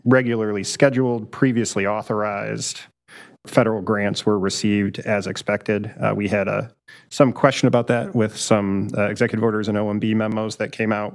Portage councilmember Chris Burns, who sits on the Metro Transit board, says the municipal bus system has also been closely monitoring their federal support, particularly reimbursement for several recently purchased buses.